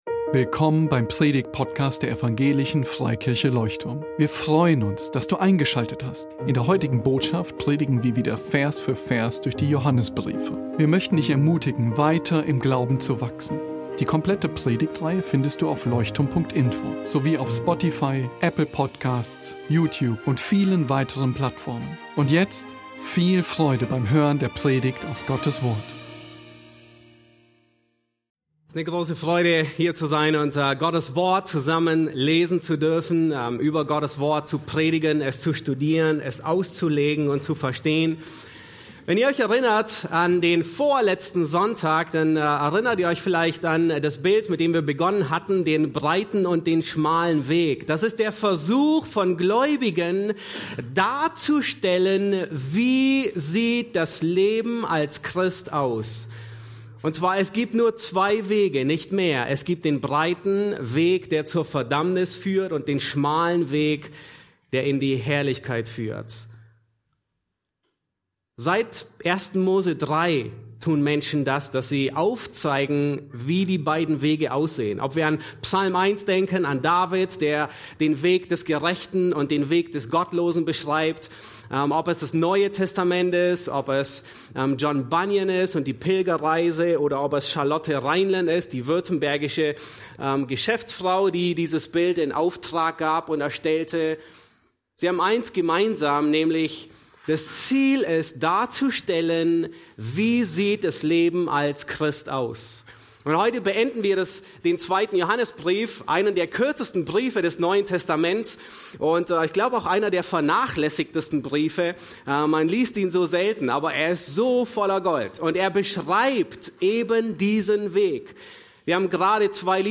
Amen. 2.Johannes 7-13 PREDIGTGLIEDERUNG 1.